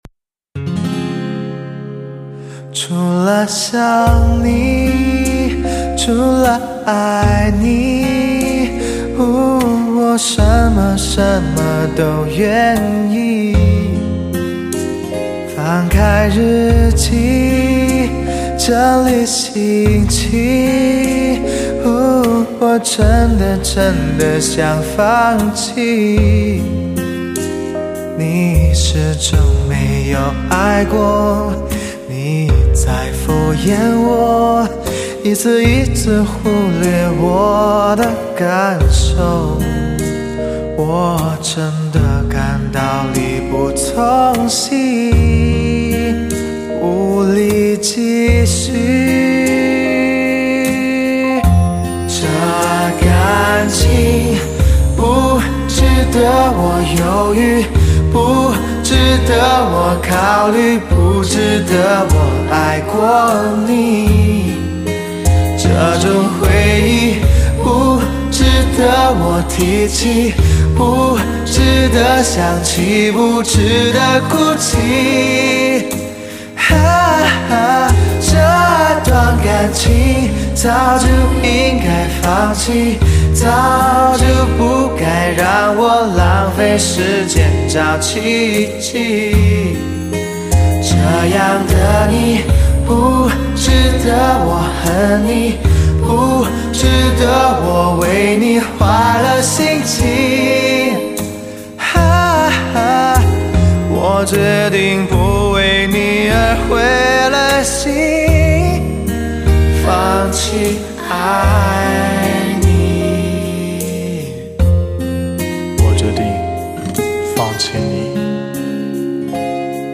唱片类型：汽车音乐